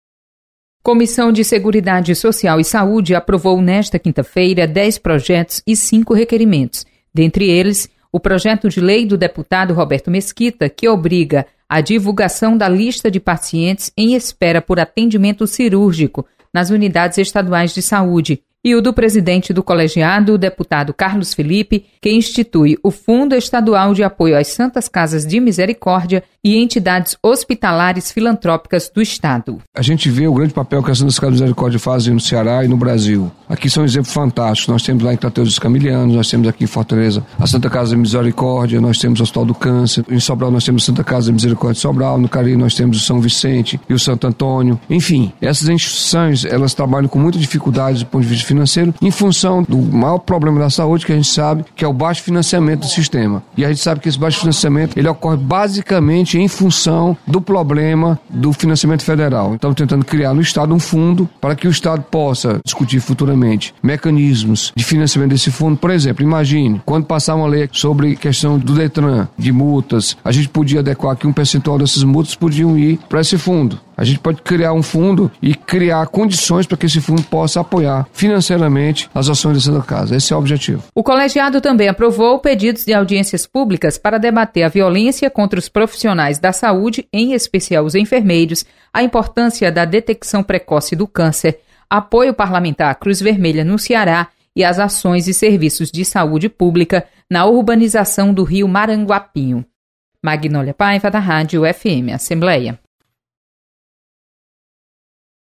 Publicado em Notícias